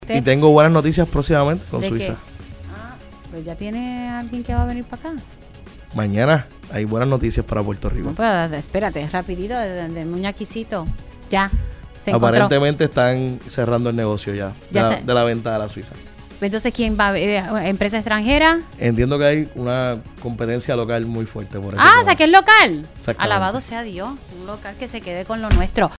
El secretario de Agricultura, Josué Rivera reveló en Pega’os en la Mañana que está en el proceso final la venta de la Suiza Dairy a una empresa local.